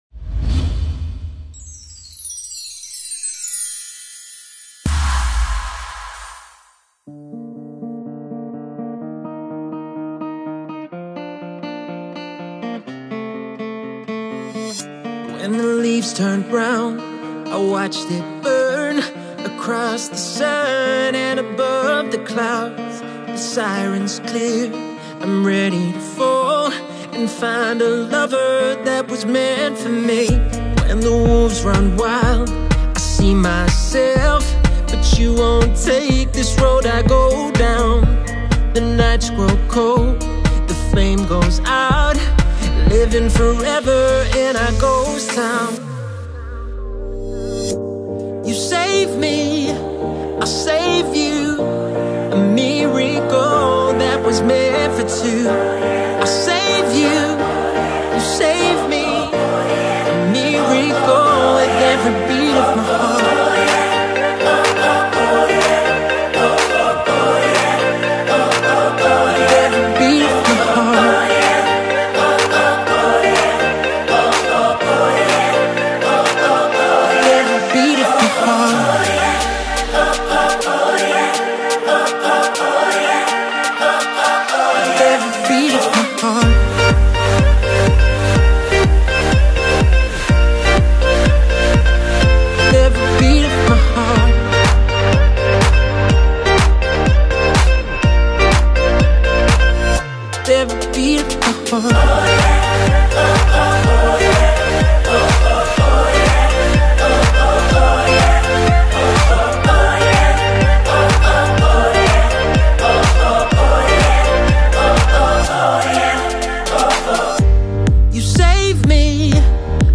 Категория: Поп Музыка